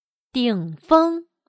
顶峰/Dǐngfēng/cima